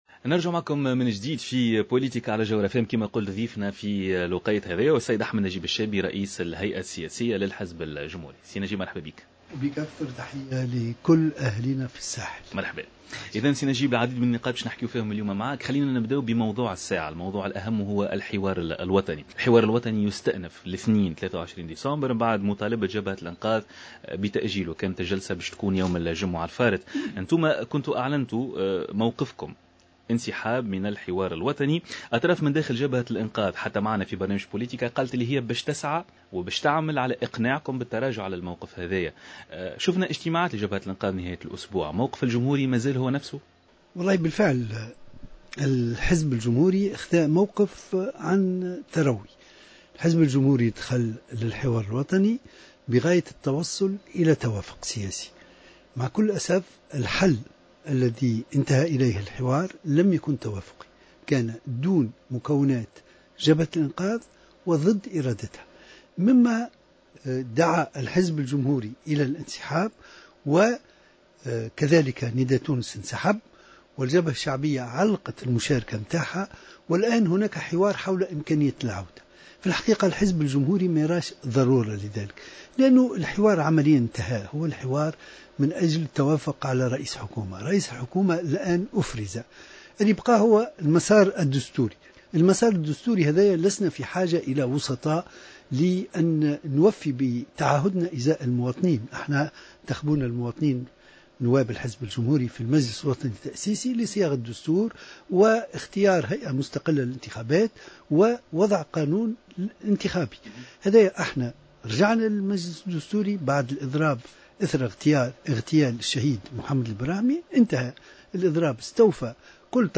أكد رئيس الهيئة السياسية للحزب الجمهوري أحمد نجيب الشابي، في تصريح لجوهرة آف آم،اليوم الاثنين 23 ديسمبر 2013 ، ان حزبه ليس له اعتراض على رئيس الحكومة مهدي جمعة الذي أفرزه الحوار الوطني رغم الاختلاف حول آلية اختياره. وأضاف الشابي ان الجمهوري سيحكم على الحكومة القادمة من خلال استقلاليتها ومدى استعدادها لتنفيذ البرنامج المتفق عليه من خلال مراجعة التعيينات وكشف المسؤولين عن الاغتيالات بالاضافة الى المسائل الاقتصادية العاجلة .